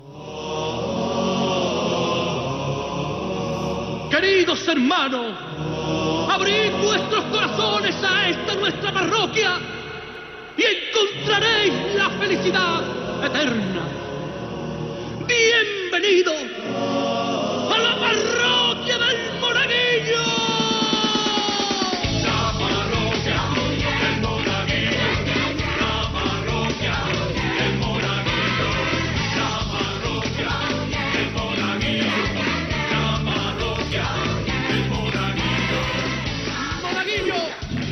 Careta i inici del programa.
Entreteniment